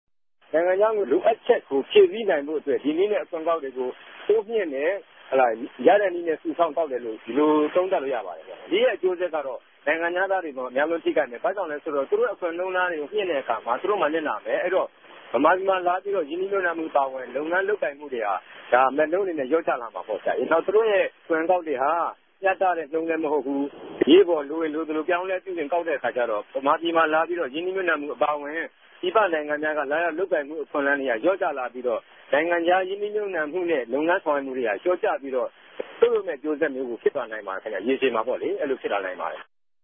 စီးပြားရေးပညာရြင်